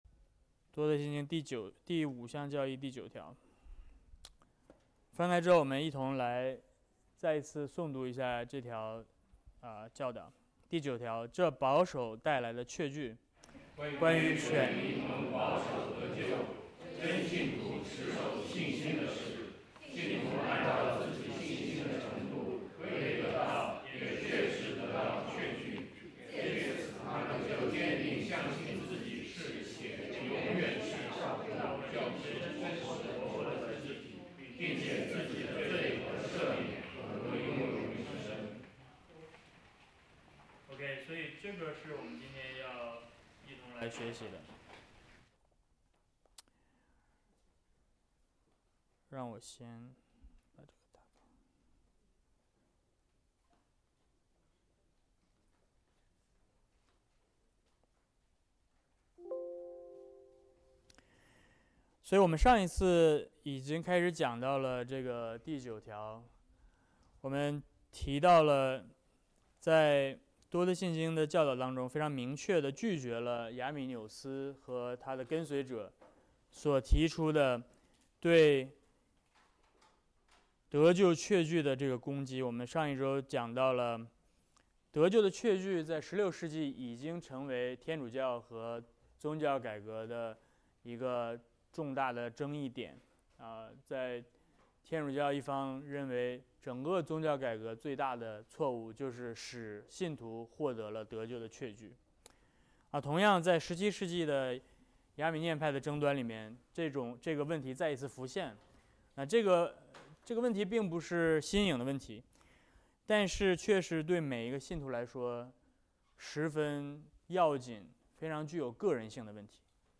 Series: 教理课程《多特信经》 Service Type: 主日学课程